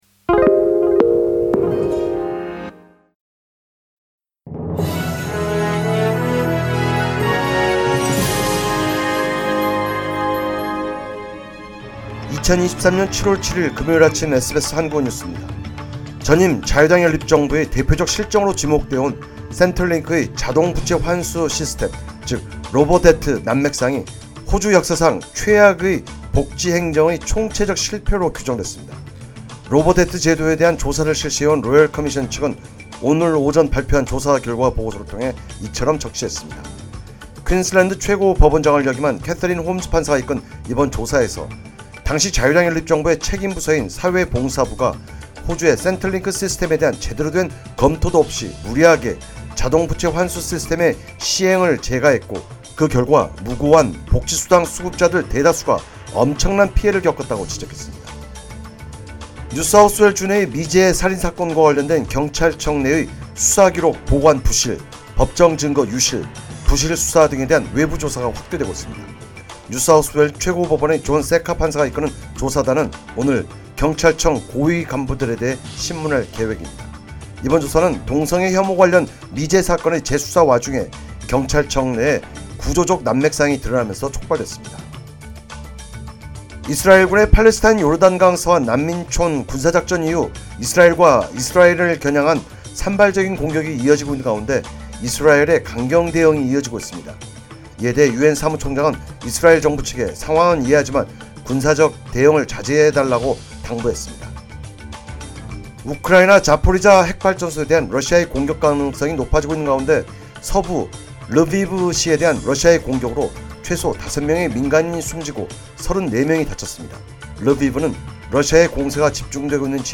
2023년 7월 7일 금요일 아침 SBS 한국어 뉴스입니다.